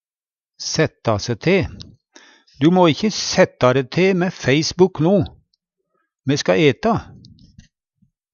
setta se te - Numedalsmål (en-US)